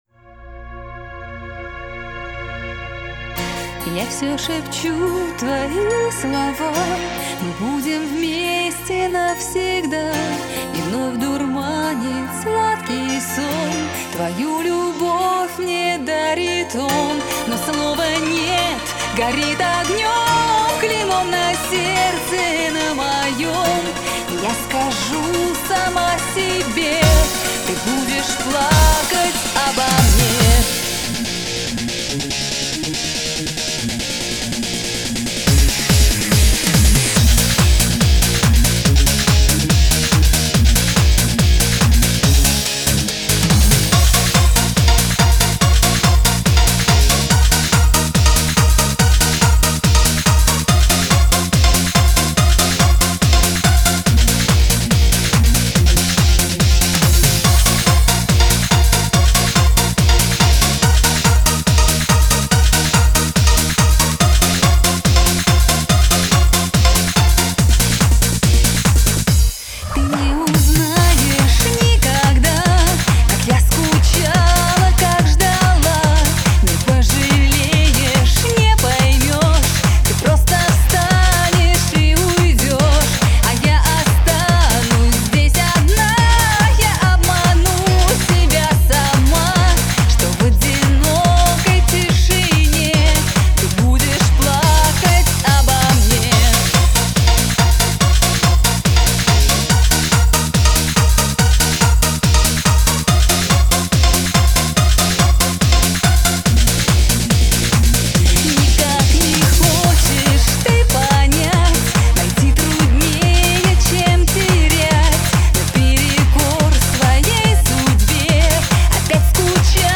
Жанр: Эстрада, Попса